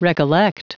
Prononciation du mot recollect en anglais (fichier audio)